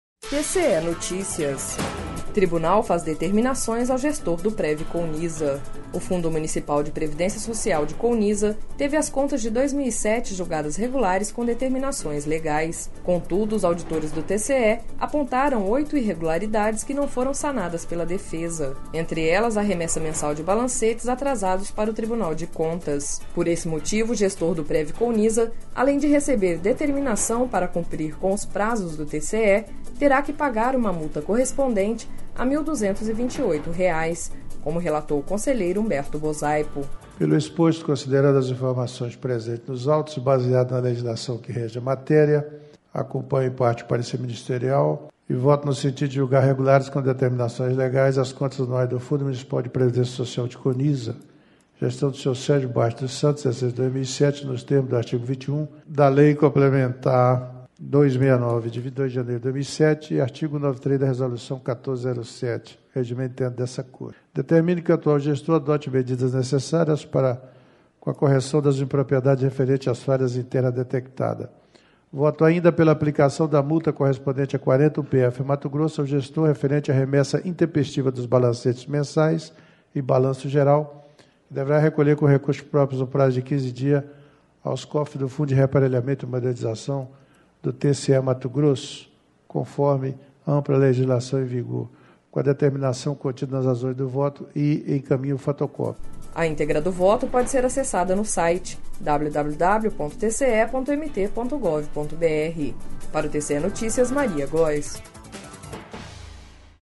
Sonora: Humberto Bosaipo – conselheiro do TCE-MT